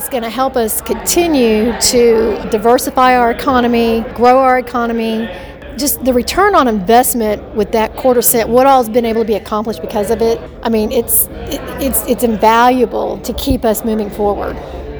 The Green Country Republican Women's Club luncheon on Thursday, February 25th, at Hillcrest Country Club in Bartlesville featured Guest Speaker